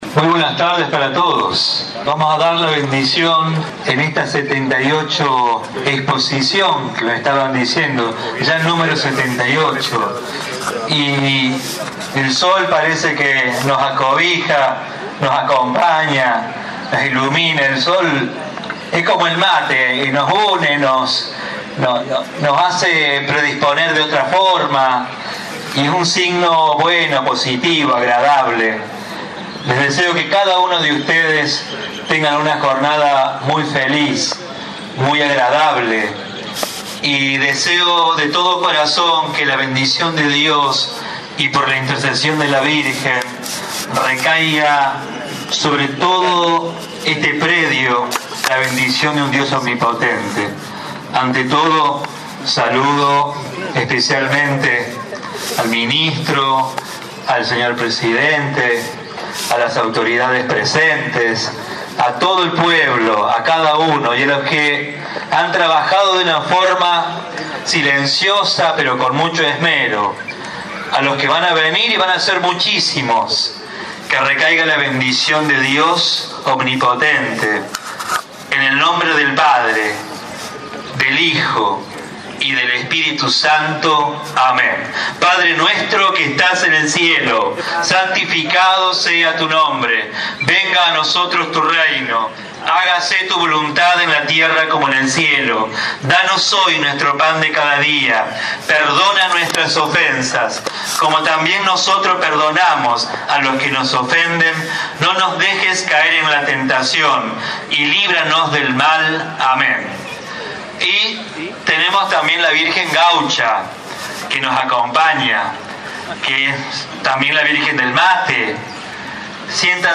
Ayer 10 de septiembre se realizó la apertura oficial en el patio principal de la expo.